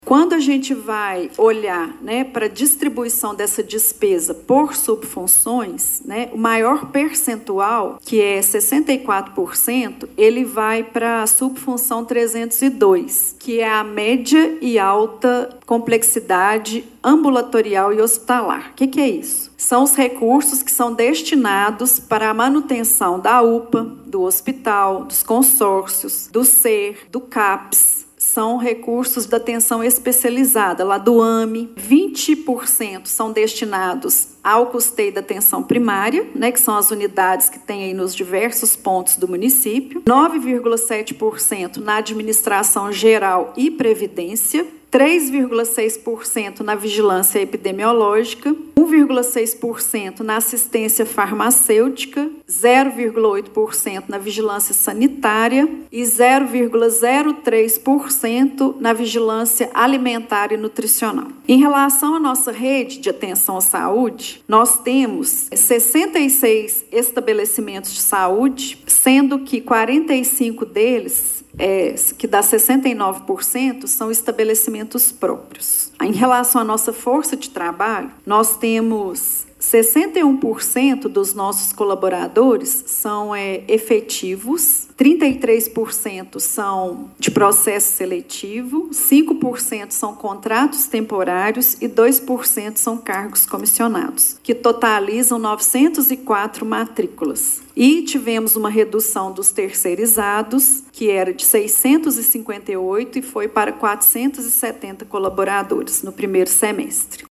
Em audiência pública realizada nesta segunda-feira (18), a Secretaria Municipal de Saúde de Pará de Minas apresentou o 1º Relatório Detalhado do Quadrimestre Anterior (RDQA) de 2025.